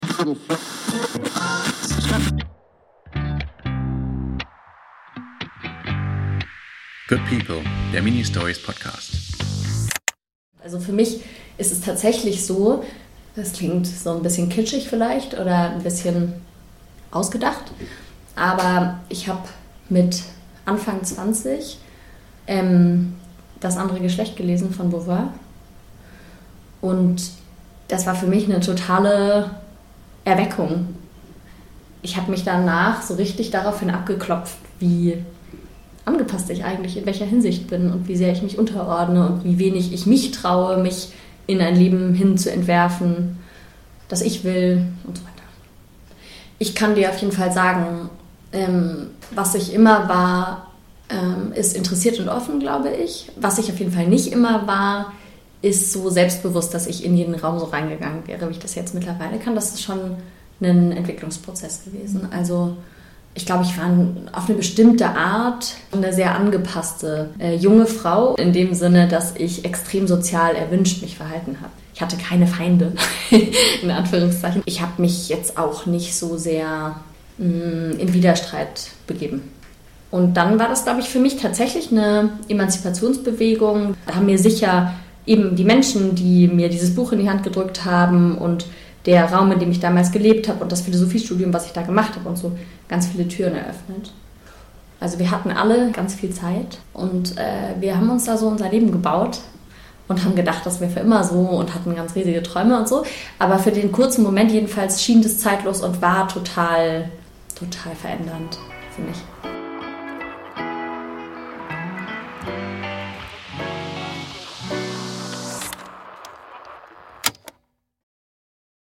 Mitwirkende: